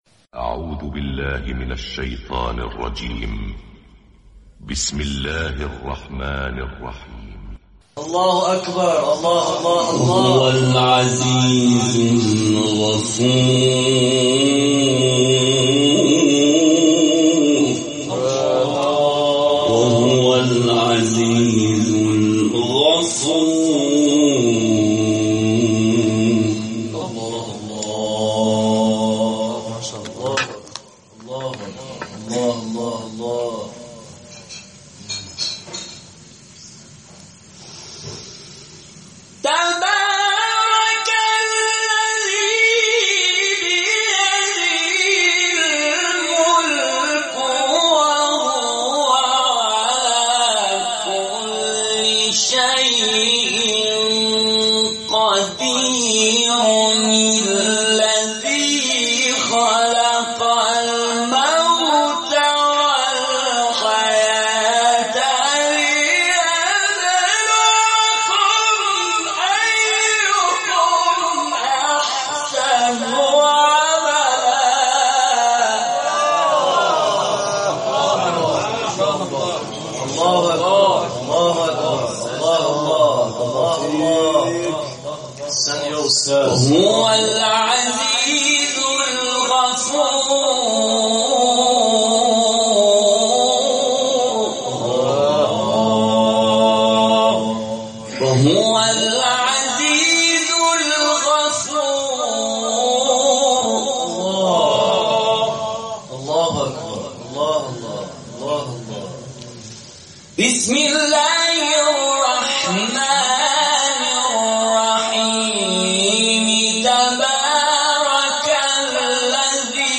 تلاوت در کانال‌های قرآنی/